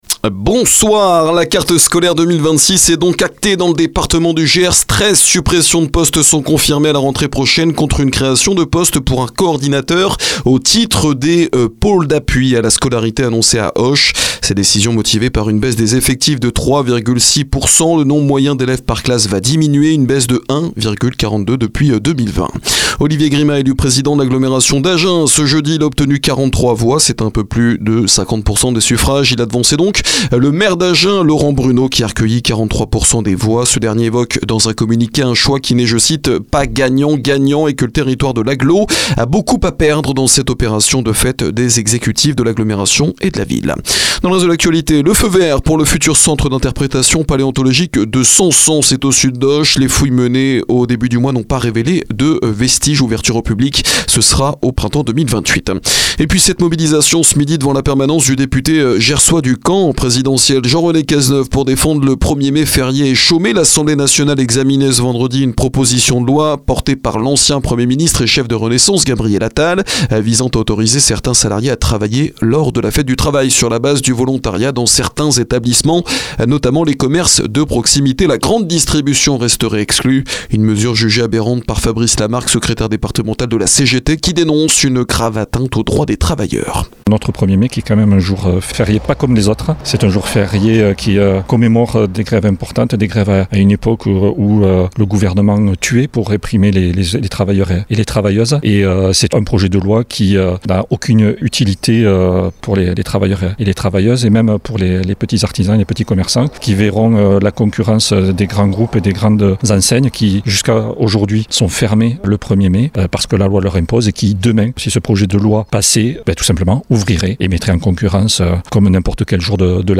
Flash HPY 10 avril soir